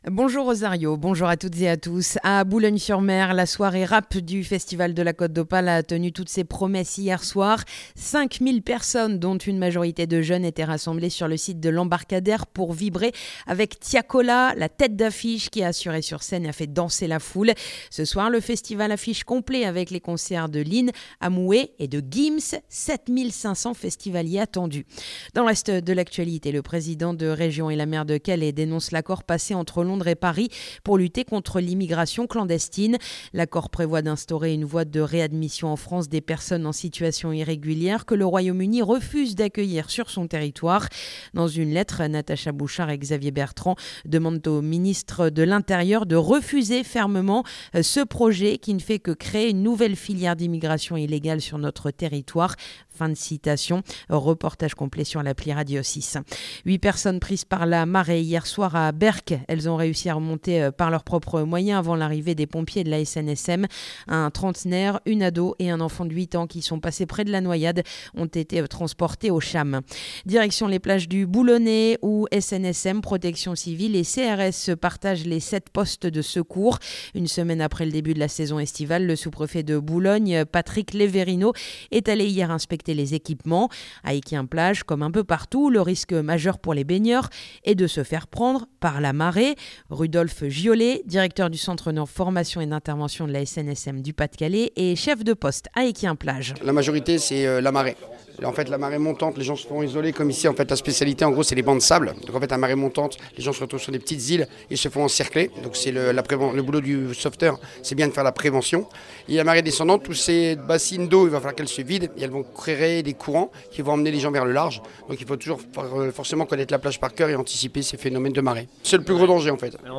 Le journal du samedi 12 juillet